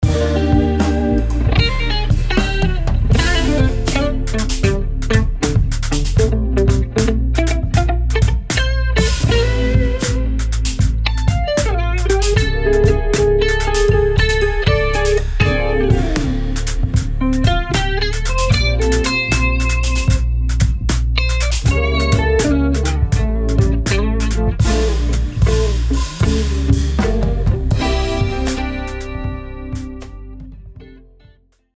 Rehearsal Mix 2
Rehearsal-Mix-2.mp3